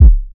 SouthSide Kick Edited (15).wav